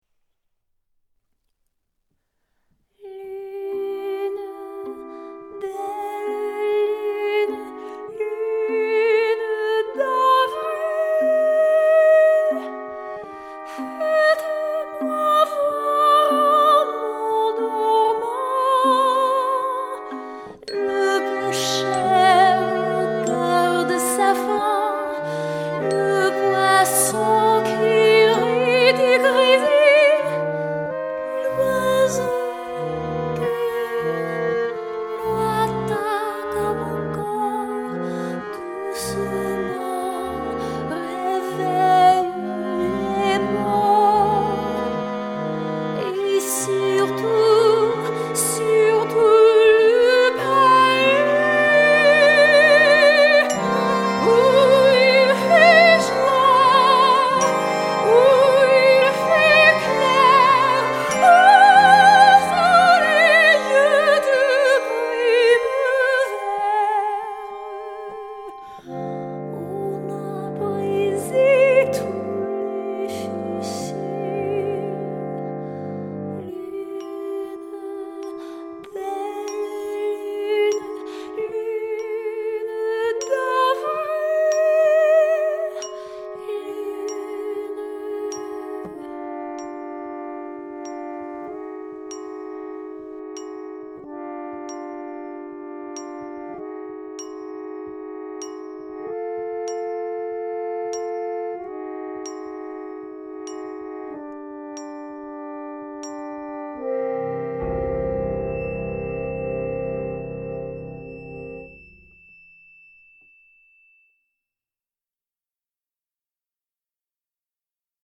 A Concert of Vocal and Piano Music from Hildegard von Bingen to Poulenc
Early Music, Classical and Art Songs Concert